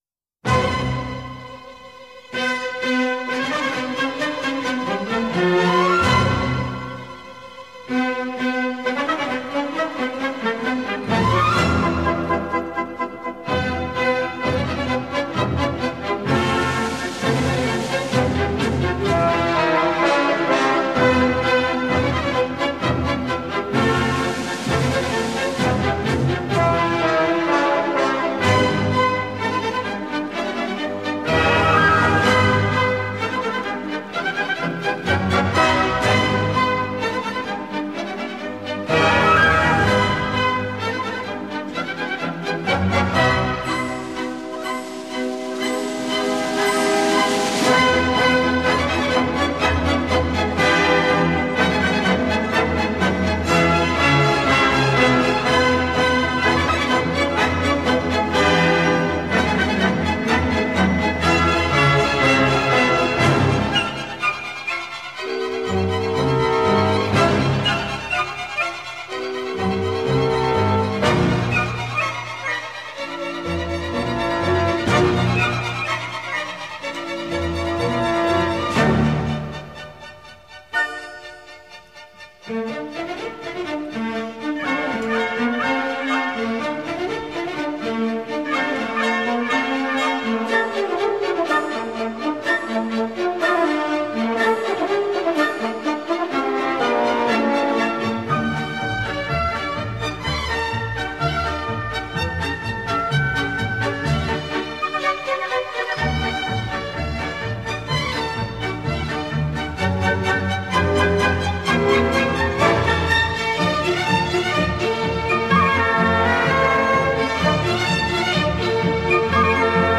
Twelve instrumental pieces